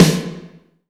SNARE 009.wav